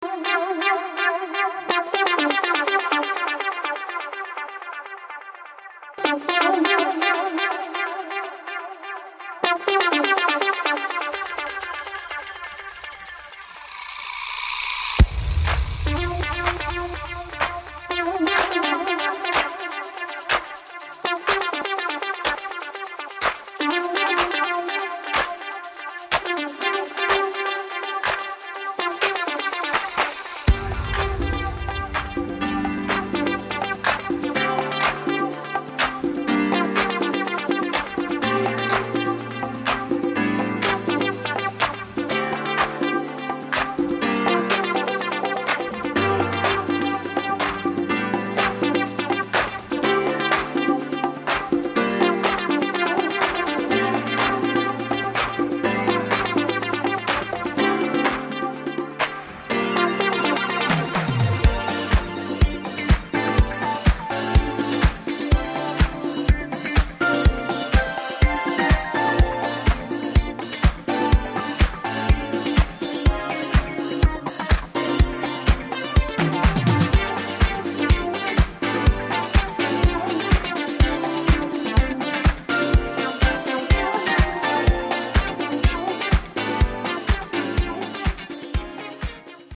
This is also the most dynamic boogie disco!